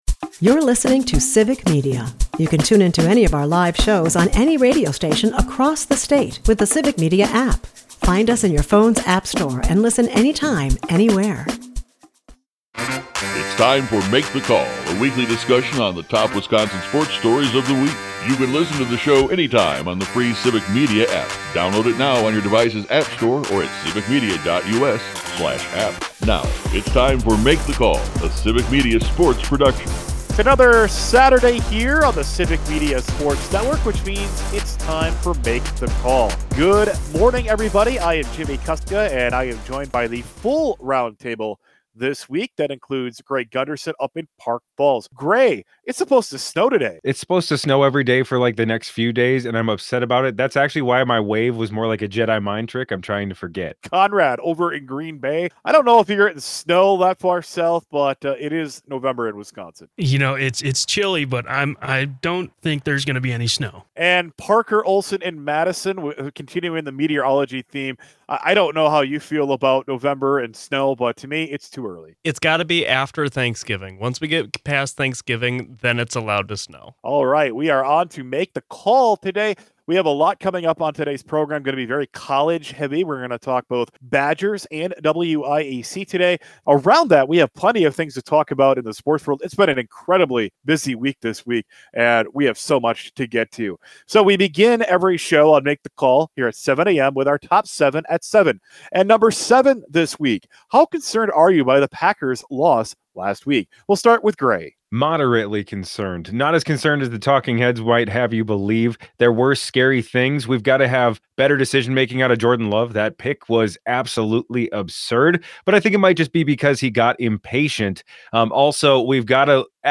The roundtable talks a little Badgers and plenty of Packers as they gear up for their Monday night matchup with the Eagles.